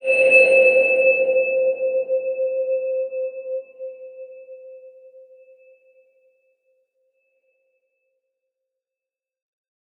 X_BasicBells-C3-pp.wav